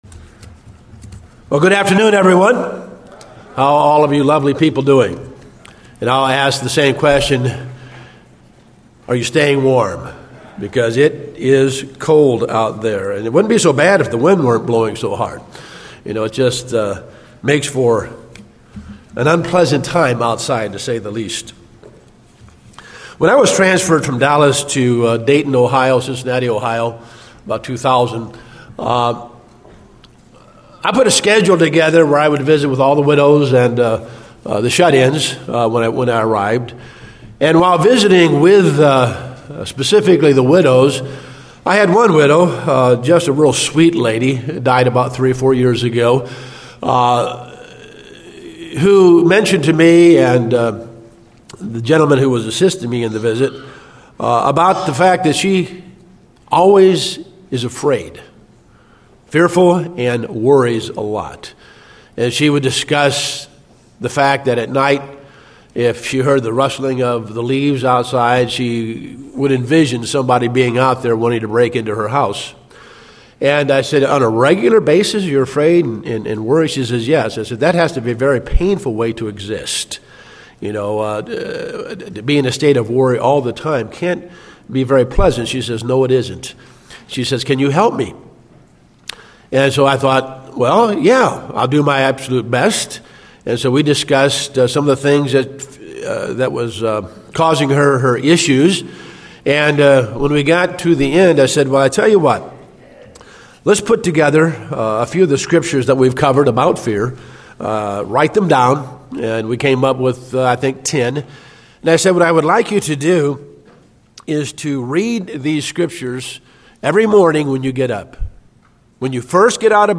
Sermons
Given in Dallas, TX